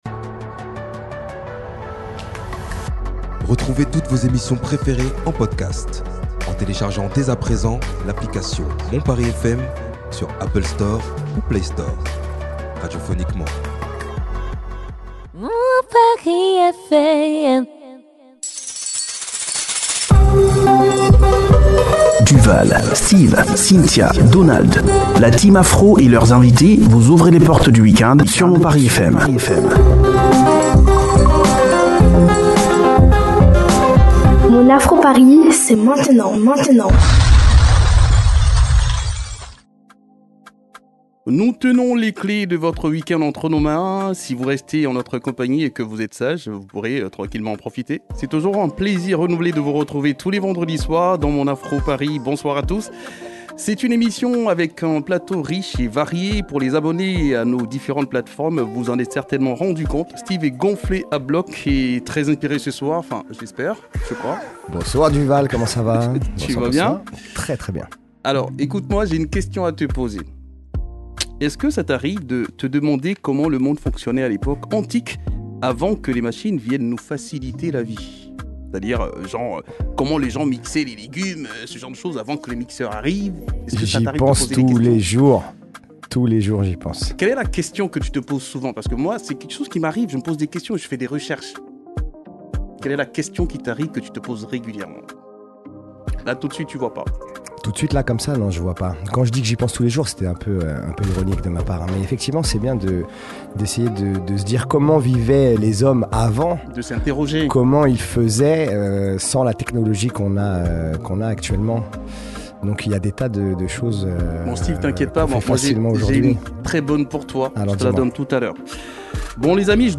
Le talkshow africain reçoit des artistes d’horizon